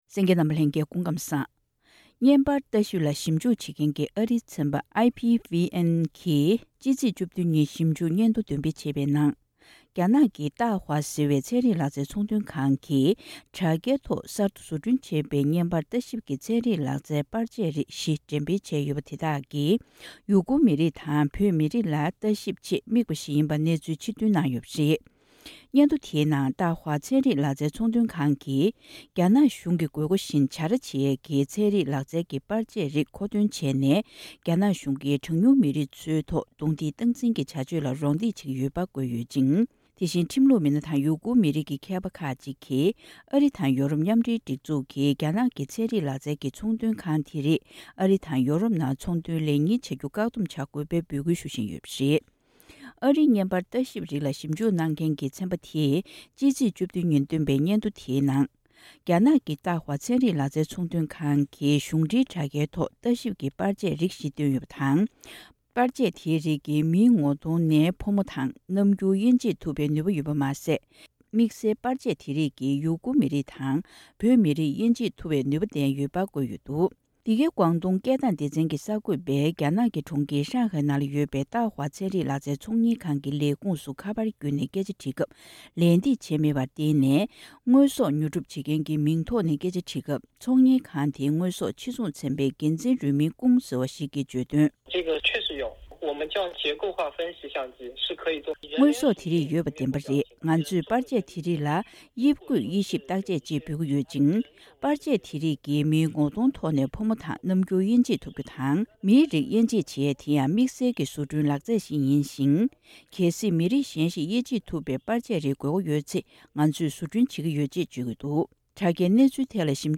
བཀའ་འདྲི་ཕྱོགས་སྒྲིག་ཞུས་པ་ཞིག་སྙན་སྒྲོན་ཞུས་གནང་གི་རེད།